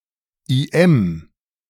An unofficial collaborator[1] or IM (German: [iˈʔɛm]